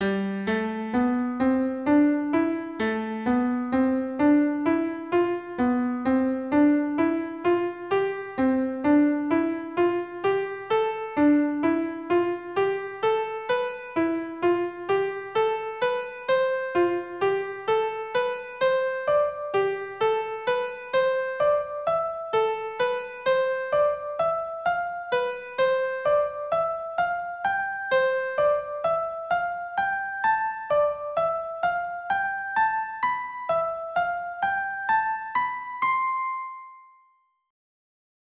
Accordeon diatonique et Musiques Traditionnelles
5 - Gamme 6 par 6
Gamme de Lam par 6 en montant